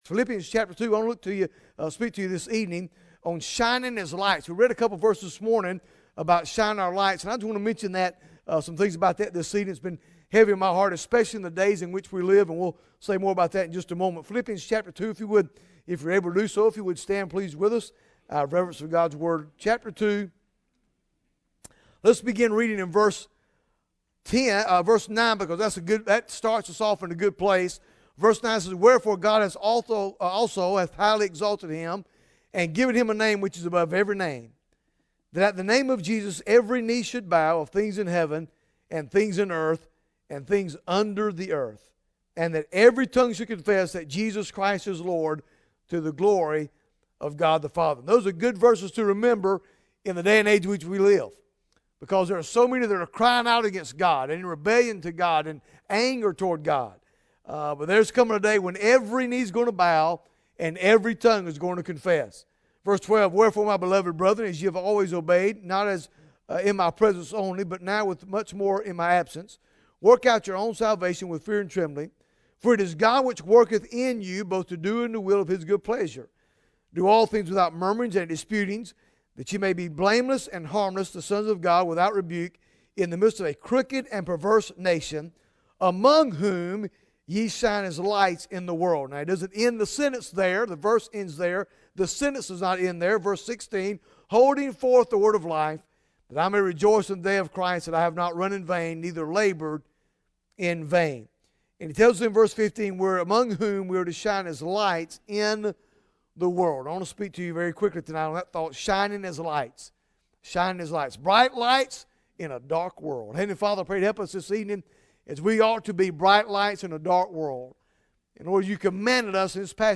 Bible Text: Philippians 2 | Preacher